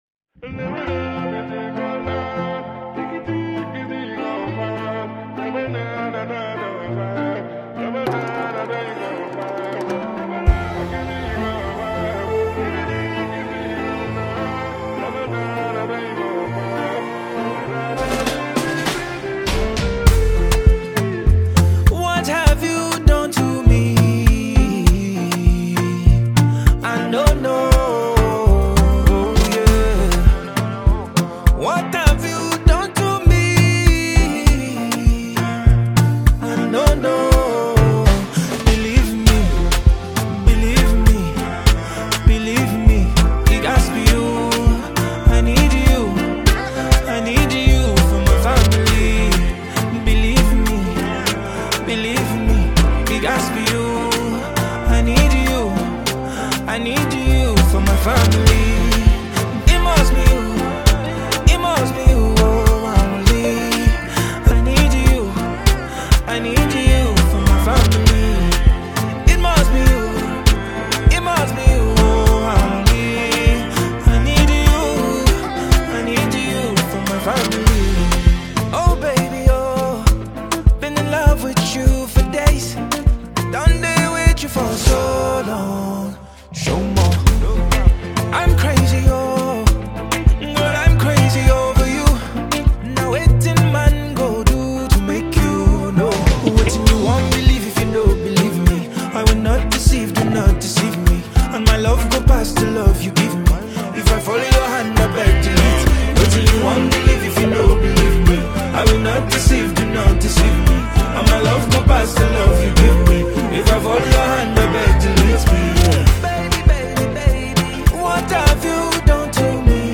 Nigerian Singer
emotional